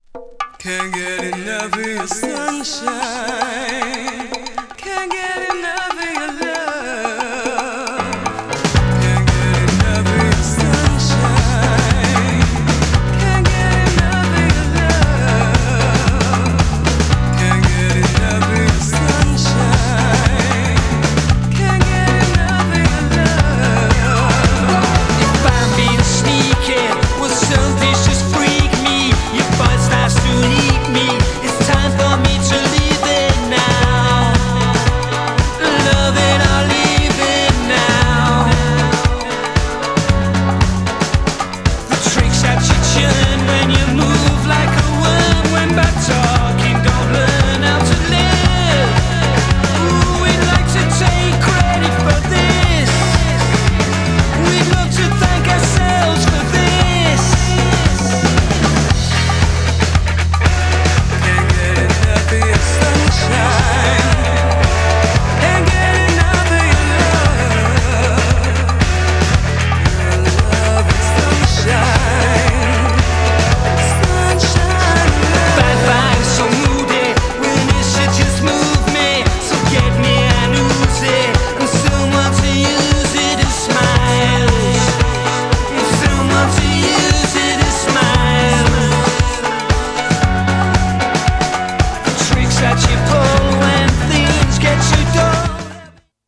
ロックなオリジナル12"MIXも収録!!
GENRE House
BPM 121〜125BPM